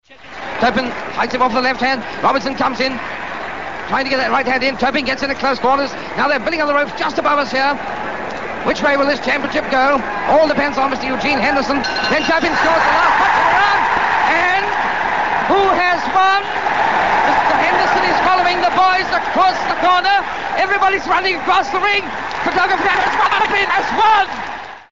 The voice of sport for a generation.
Raymond Glendenning’s distinctive tones were heard on the Country’s biggest fixtures for almost thirty years.
Not just football either: you would also hear him on racing commentaries, tennis and show jumping.